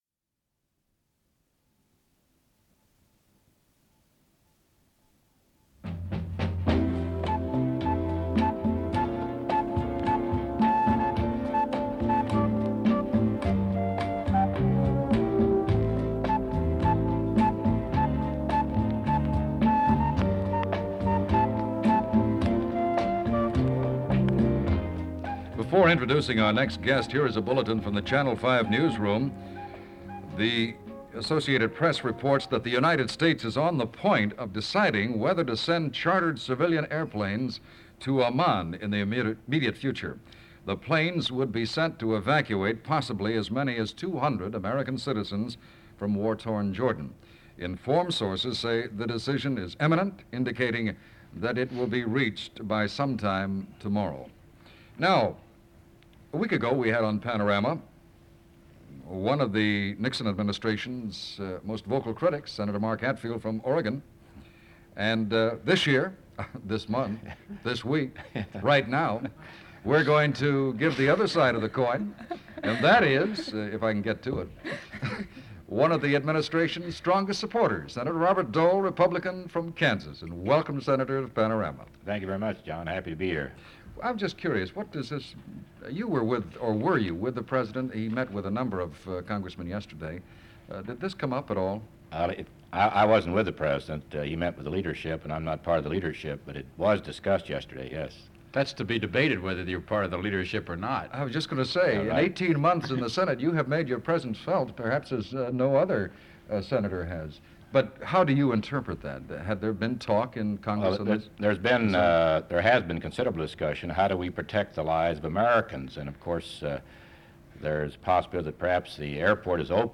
Part of Interview of Bob Dole on Vietnam, the Middle East, and Spiro Agnew